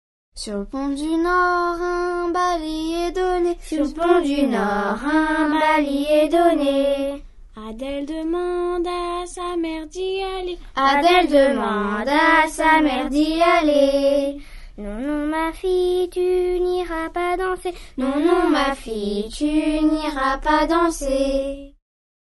Musiques traditionnelles.